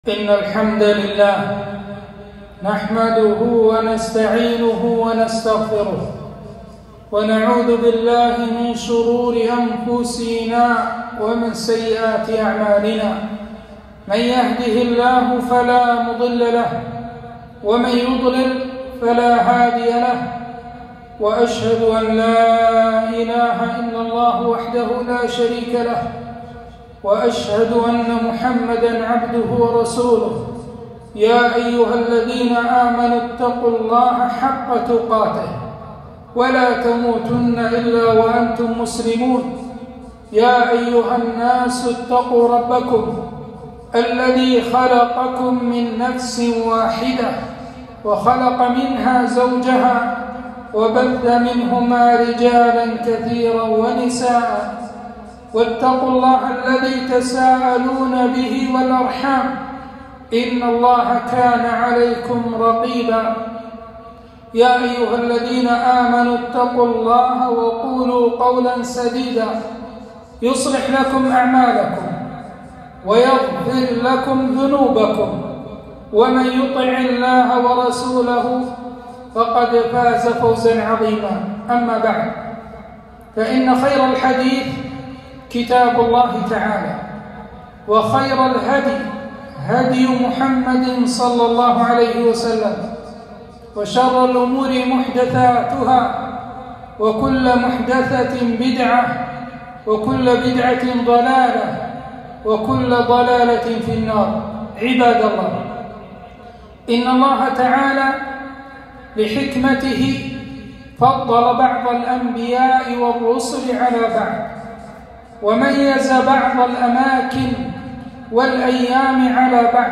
خطبة - سنن وآداب وفضائل يوم الجمعة